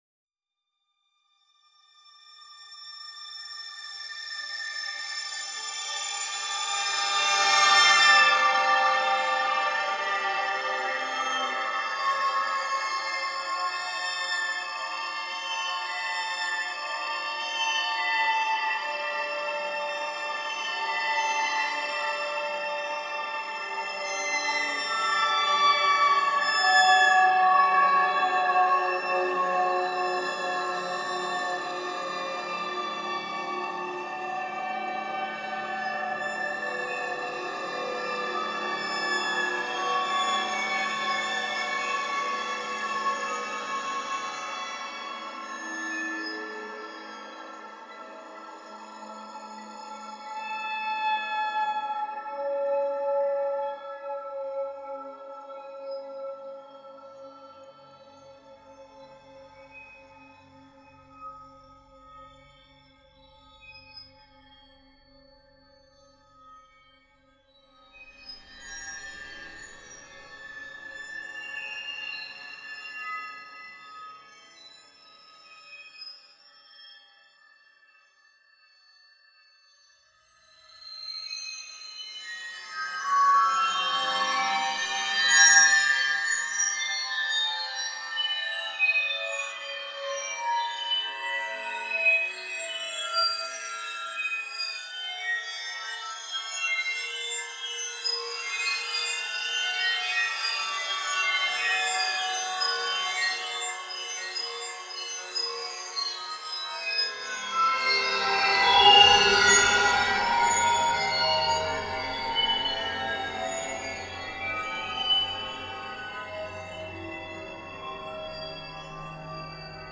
Child's Play:a Collection of Three Pieces for Instruments and Fixed Electronic Media
Acousmatic, Chamber Ensemble and Electronics, Electroacoustic, Fixed Media, Instrument and Electronics, Music Composition
"Child's Play" is a collection of three pieces for instruments and fixed electronic media inspired by the sounds of my daughter's toys. The first piece, "With My Eyes Shut" is for clarinet and fixed electronic media, the second piece, "Noises Everywhere" is for fixed electronic media, and the final piece, "Child's Play" is scored for flute, clarinet, cello, piano, and fixed electronic media.
All of the electronic material is derived from this single sound.
The toy has a unique resonance that provides the overall sonic timbre and pitch material of the piece.
In the piece, the instruments and fixed electronic medium share the rhythmic responsibility, creating a regular, but often syncopated texture.